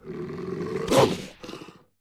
Cri de Grondogue dans Pokémon Écarlate et Violet.